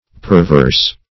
Perverse \Per*verse"\ (p[~e]r*v[~e]rs"), a. [L. perversus turned